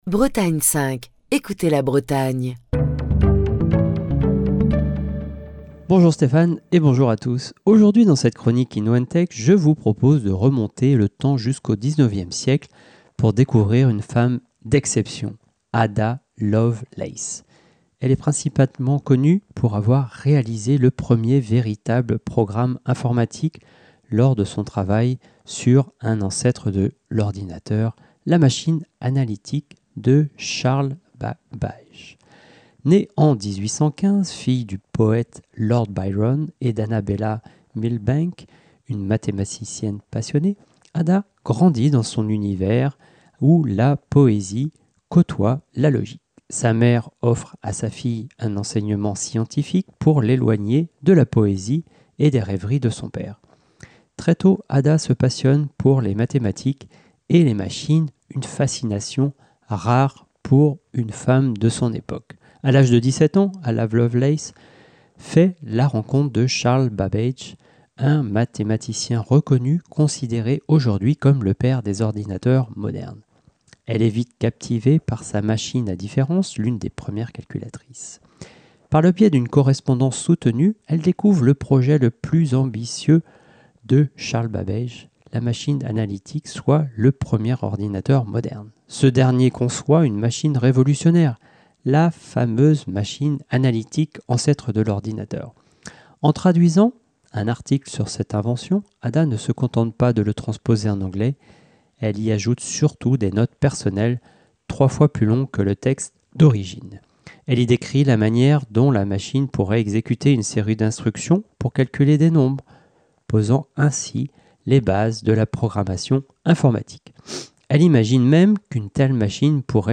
Chronique du 28 octobre 2025.